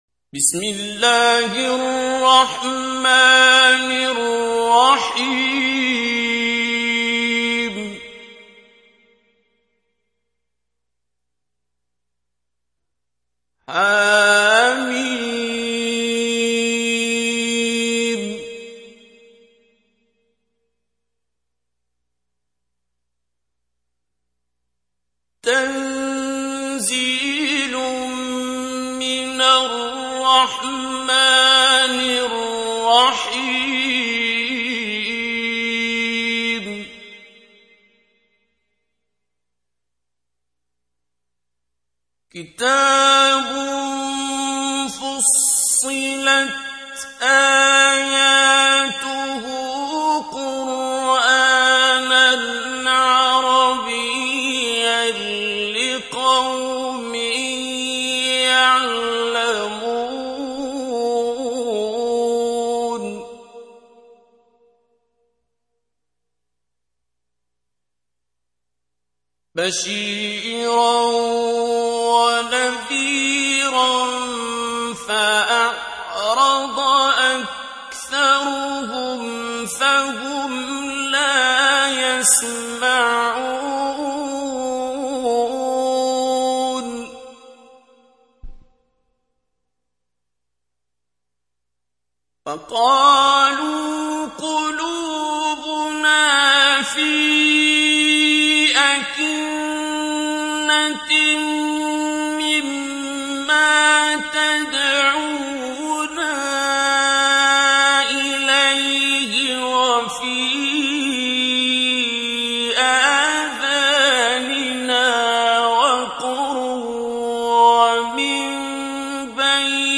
تحميل : 41. سورة فصلت / القارئ عبد الباسط عبد الصمد / القرآن الكريم / موقع يا حسين